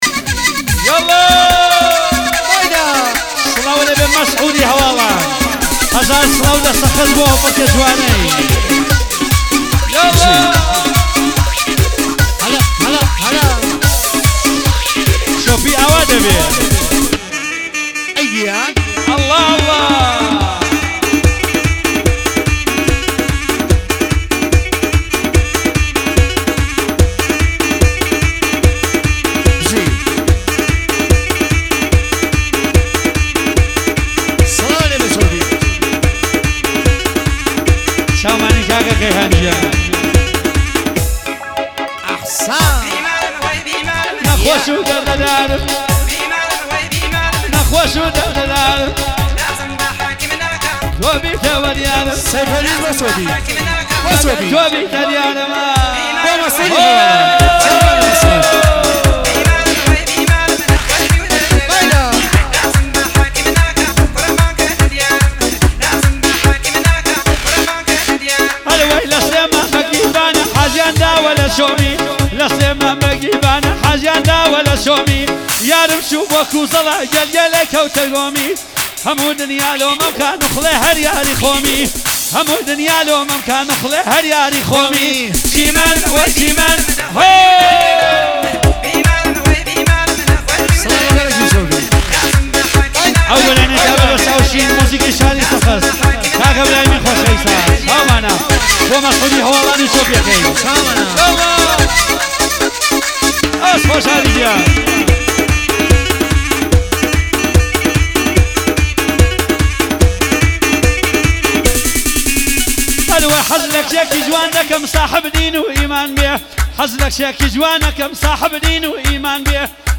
کردی جدید و شاد هلپرکه
Kurdish Music
دانلود آهنگ شاد هلپرکه